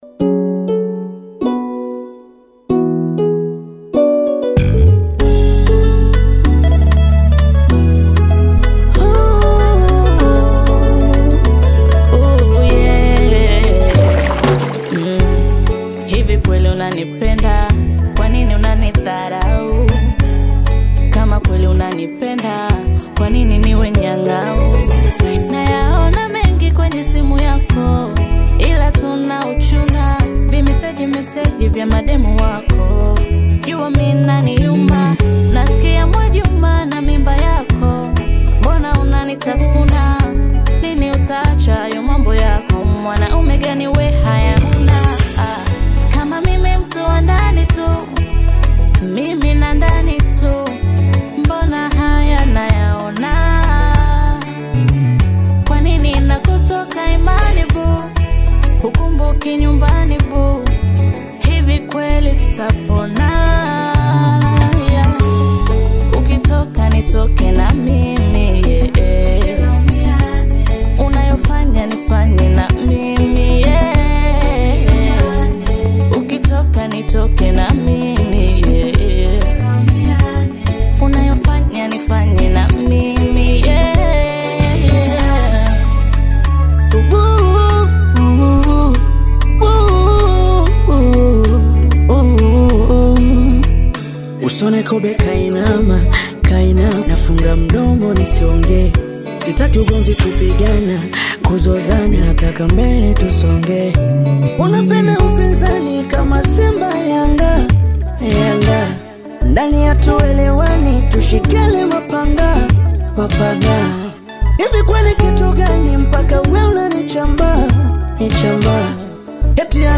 bongo flava
African Music